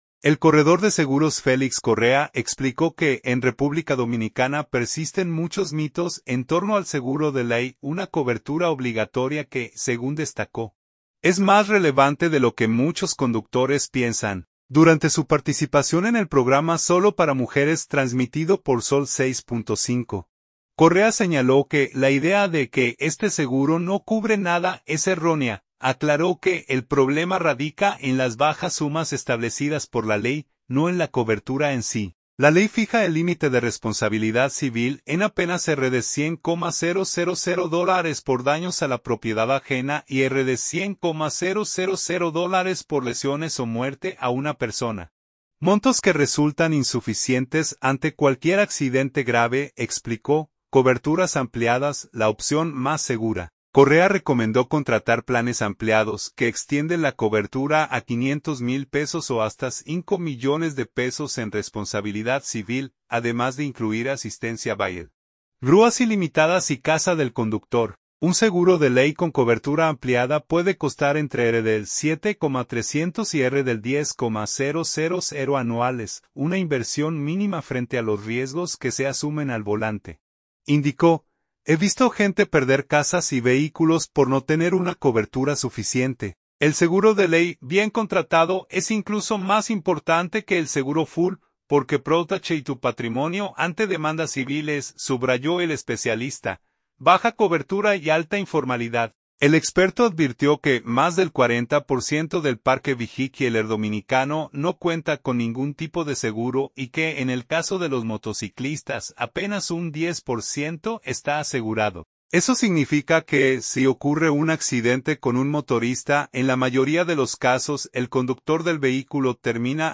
Durante su participación en el programa “Solo para Mujeres” transmitido por Zol 06.5,